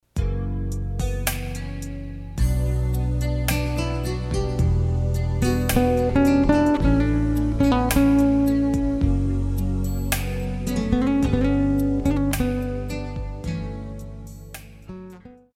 爵士
電吉他
樂團
酷派
僅伴奏
沒有主奏
沒有節拍器